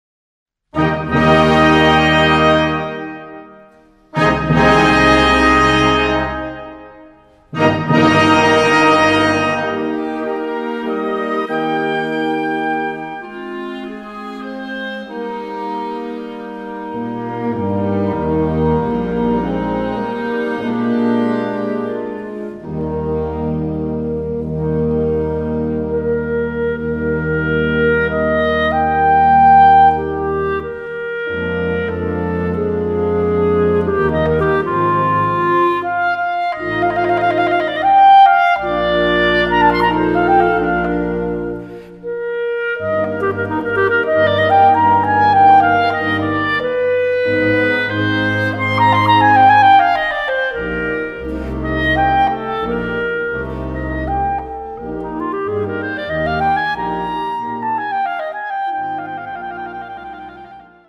Besetzung: Instrumentalnoten für Klarinette